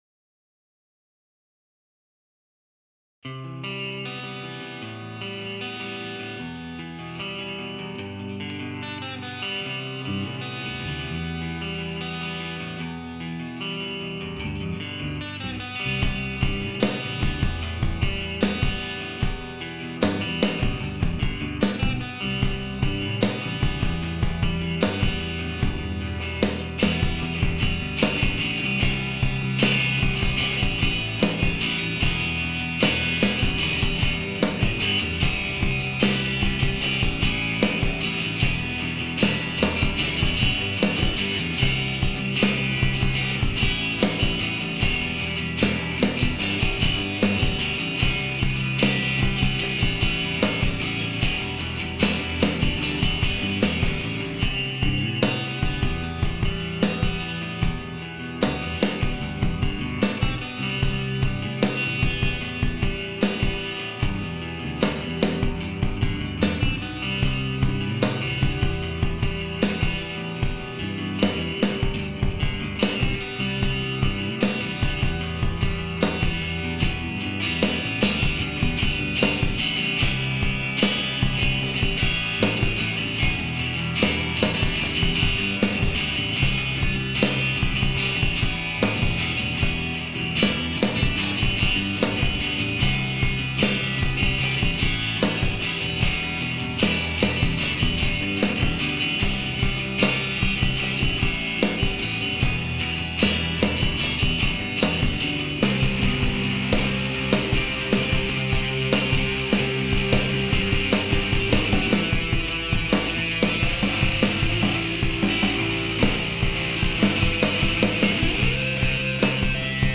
Ich stellte mir ein Trainingsprogramm für die Gitarre zusammen, entwickelte einen Gitarrensound für die Band und versuchte mich im Programmieren von Schlagzeugspuren.
schlagzeugprogrammierung (mp3, 6,138 KB)